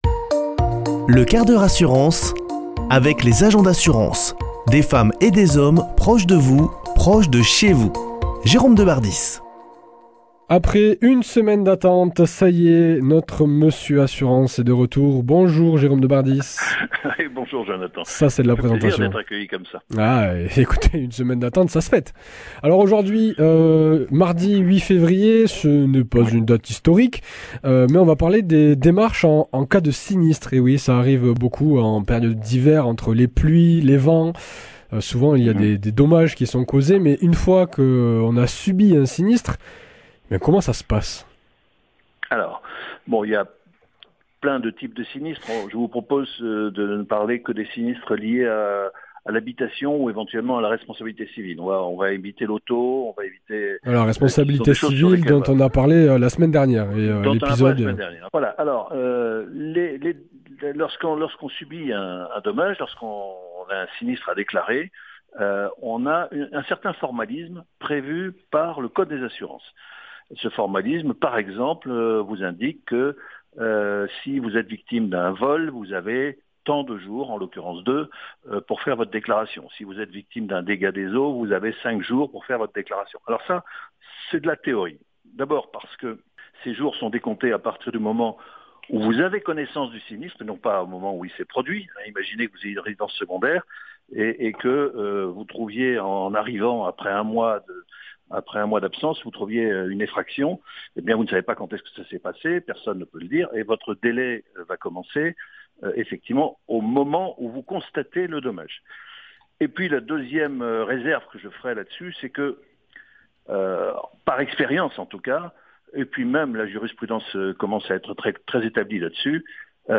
Chronique Les assurances et vous
Chroniqueur